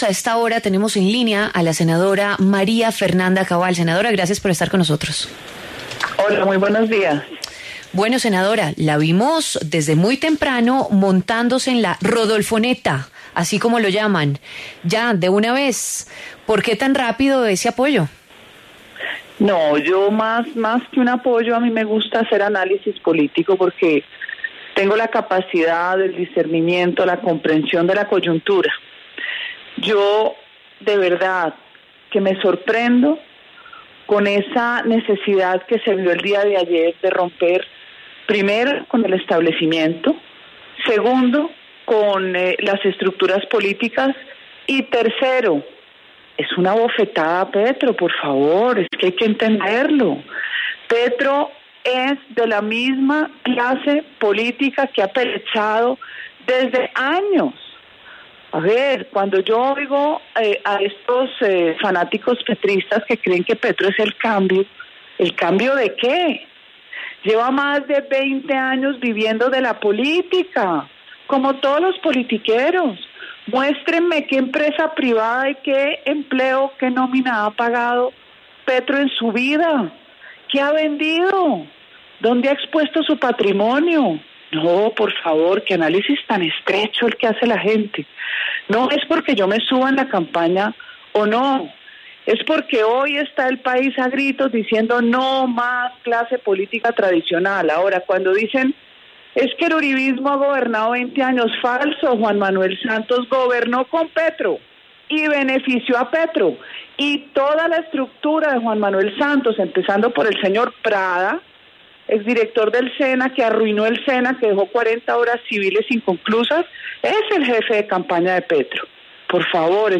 En diálogo con La W, la senadora María Fernanda Cabal se refirió a los resultados de la jornada electoral del domingo 29 de mayo, sobre la derrota del candidato Federico Gutiérrez y de su decisión de “montarse a la Rodolfoneta” para la segunda vuelta del próximo 19 de junio.